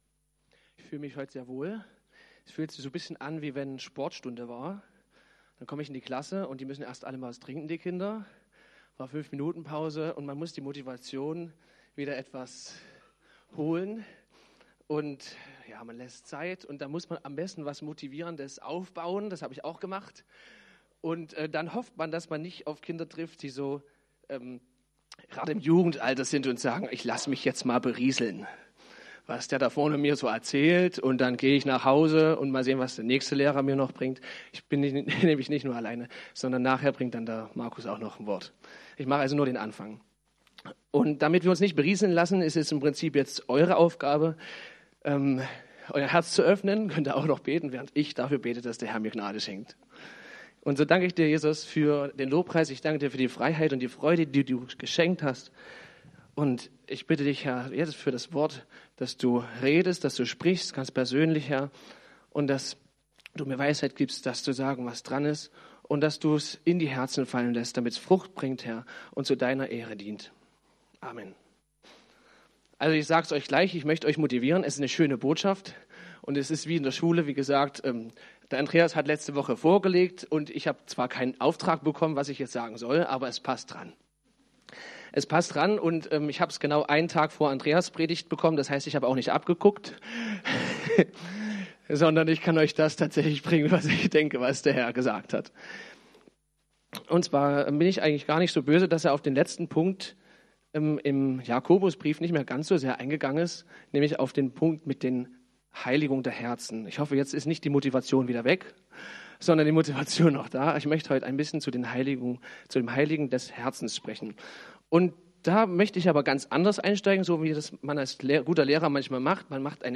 Wer will schon in die Wüste / Predigten chronologisch sortiert / 26.